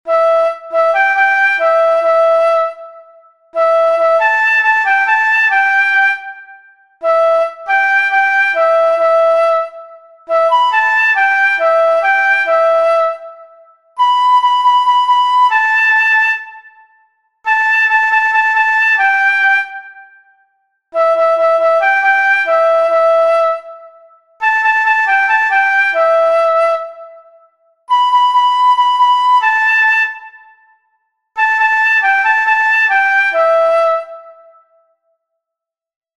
Música